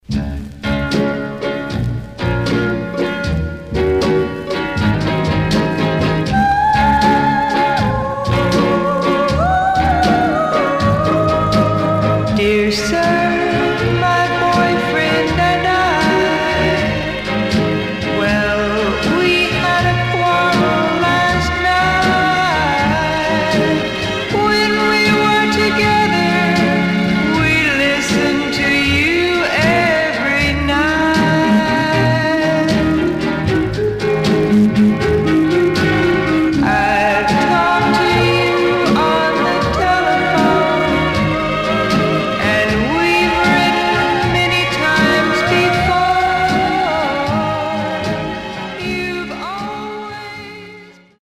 Stereo/mono Mono
White Teen Girl Groups